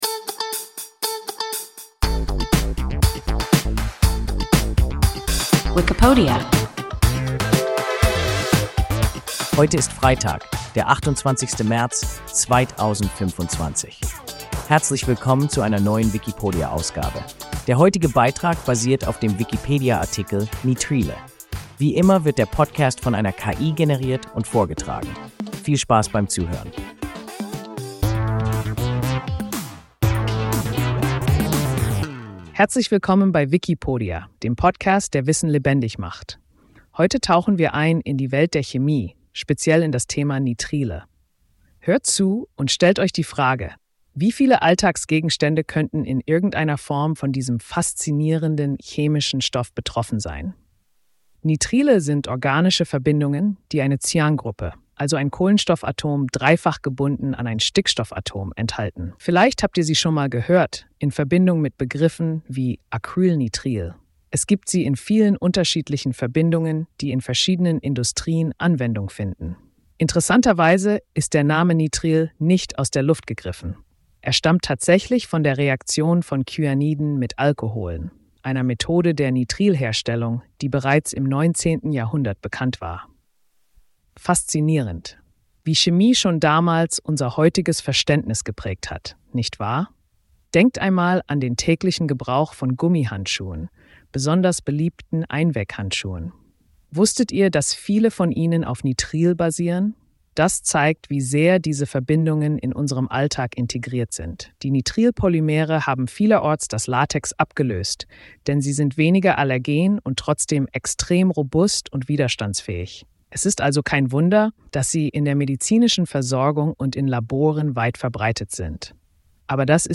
Nitrile – WIKIPODIA – ein KI Podcast